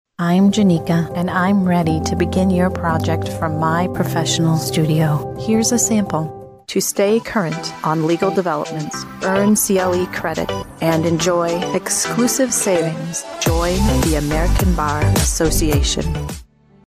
• 美式英语配音